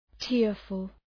{‘tıərfəl}